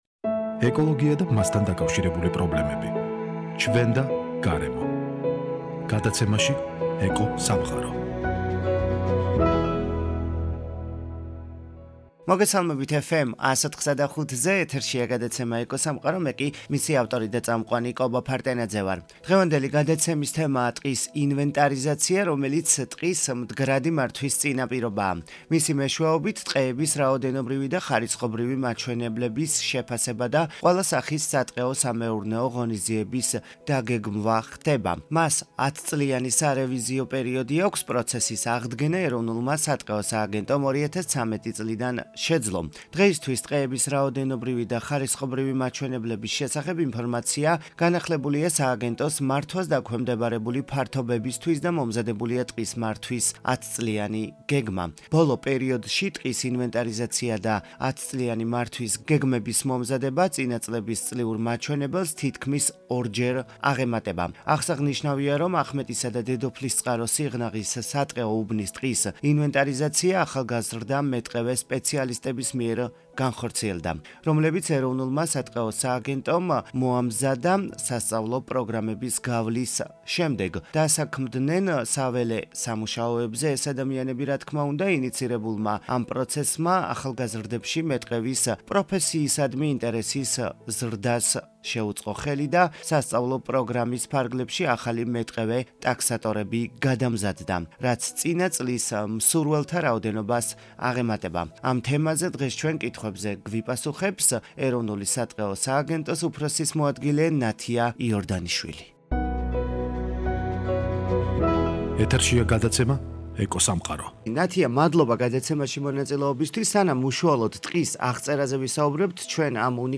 ამ თემაზე კითხვებზე გვპასუხობს, ეროვნული სატყეო სააგენტოს უფროსის მოადგილე - ნათია იორდანიშვილი.